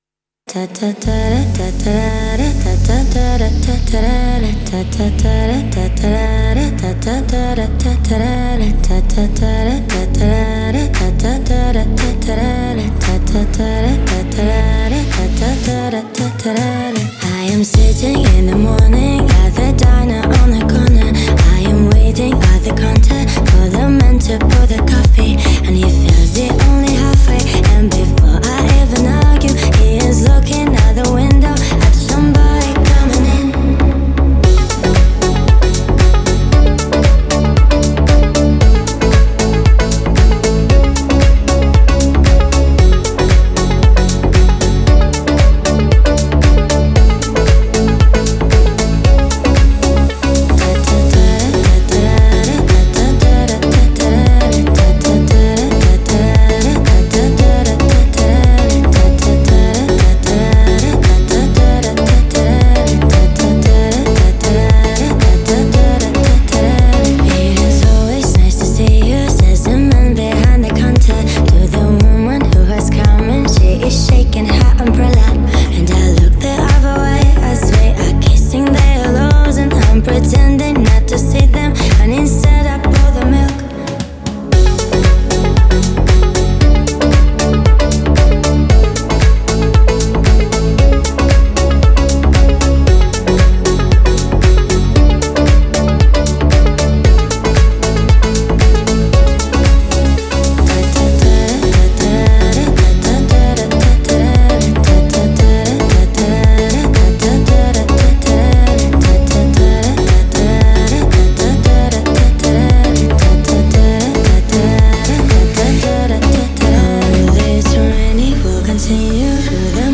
это культовая песня в жанре поп с элементами фолка